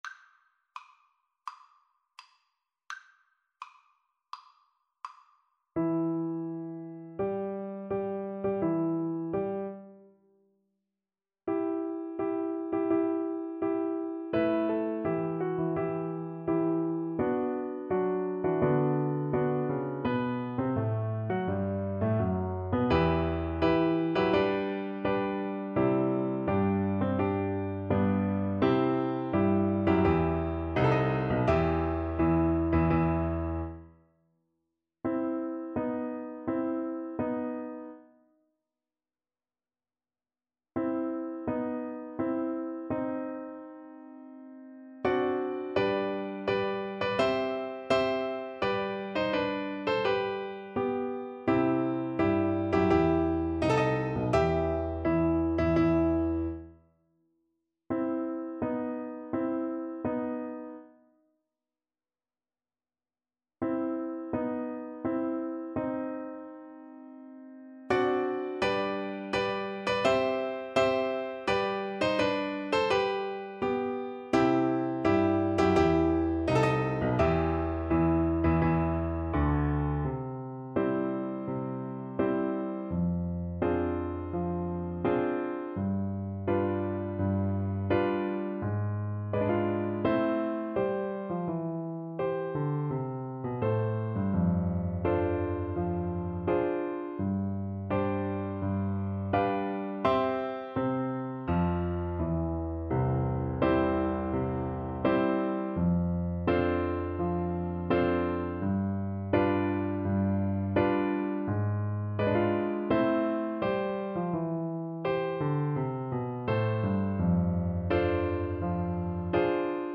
Play (or use space bar on your keyboard) Pause Music Playalong - Piano Accompaniment reset tempo print settings full screen
E minor (Sounding Pitch) (View more E minor Music for Viola )
Tempo di Marcia =84
4/4 (View more 4/4 Music)
Classical (View more Classical Viola Music)